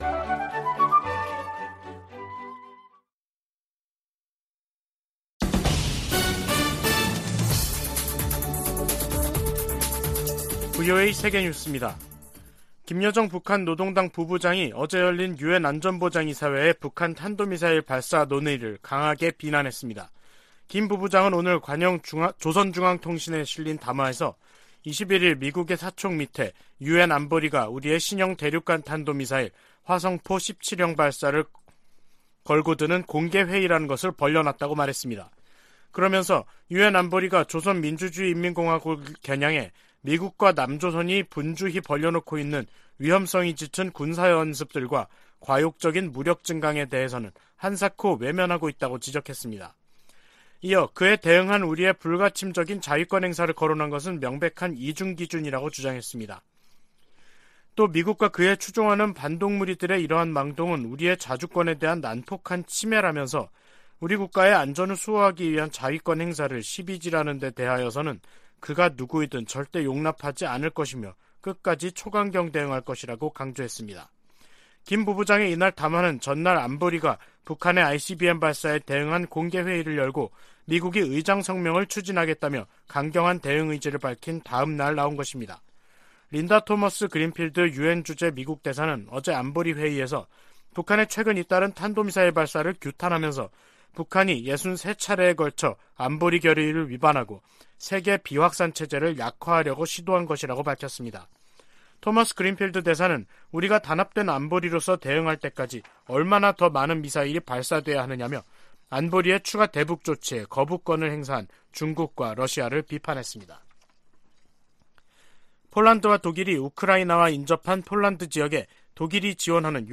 VOA 한국어 간판 뉴스 프로그램 '뉴스 투데이', 2022년 11월 22일 3부 방송입니다. 유엔 안보리가 북한의 대륙간탄도미사일(ICBM) 발사에 대응한 공개회의를 개최하고 북한을 규탄했습니다. 북한의 화성 17형 시험발사에 대응해 21일 소집된 유엔 안보리에서 중국과 러시아는 북한의 도발이 미국 때문이라는 주장을 반복했습니다.